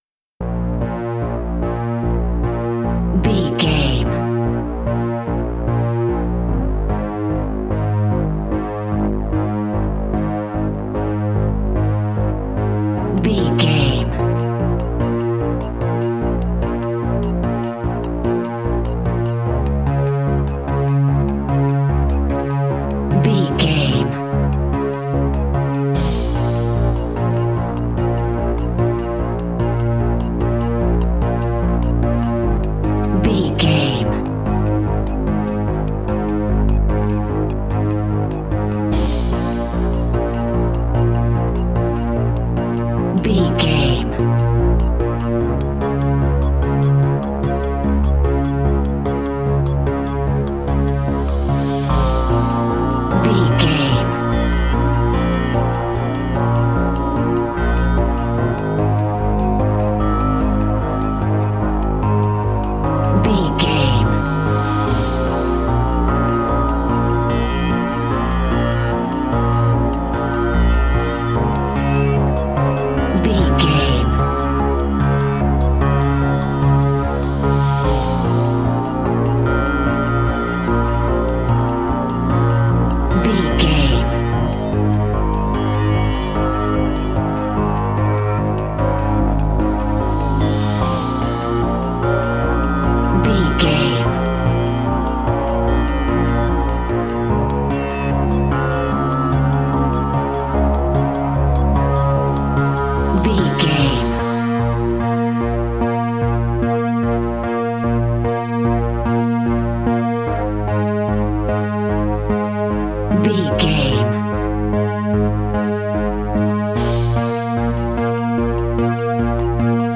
Aeolian/Minor
B♭
ominous
dark
haunting
eerie
synthesiser
drums
ticking
electronic music